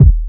kick 24.wav